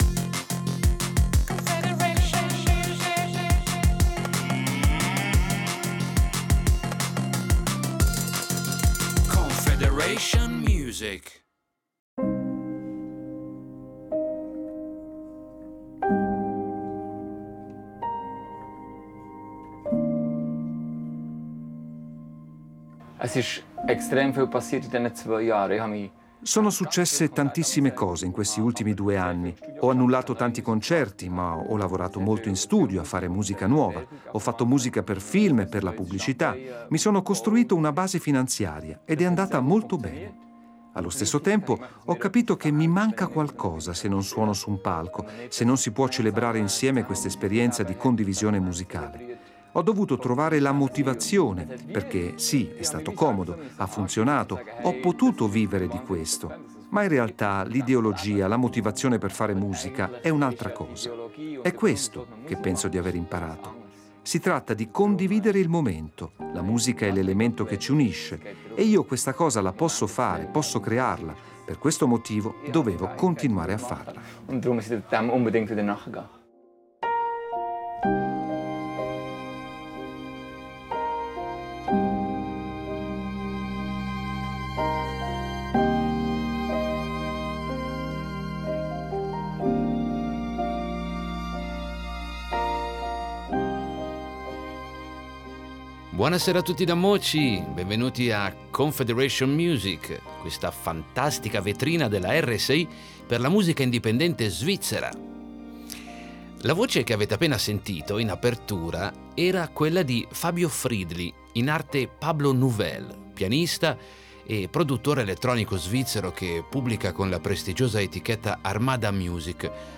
Siamo andati a trovarlo nel backstage per sapere con quale spirito affronta questo ritorno in scena e per vedere un po’ più da vicino il mondo di questo instancabile operaio della musica.